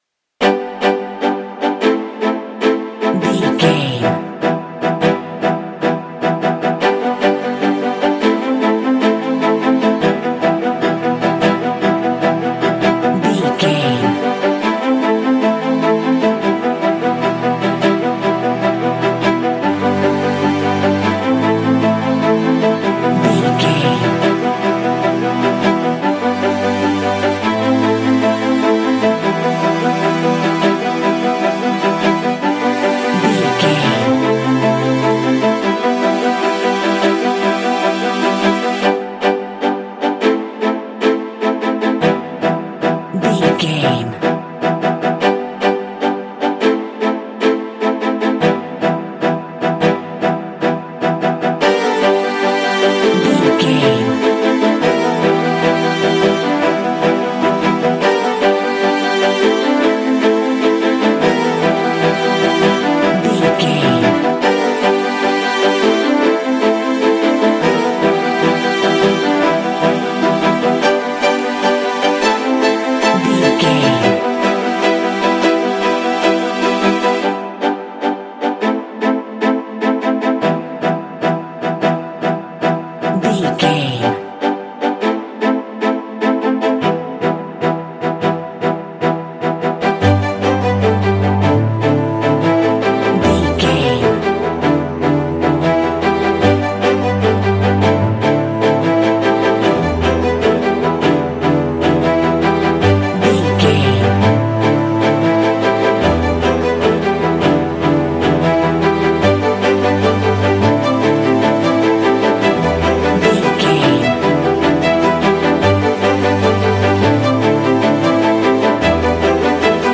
Epic / Action
Uplifting
Aeolian/Minor
bright
hopeful
elegant
indie
alternative rock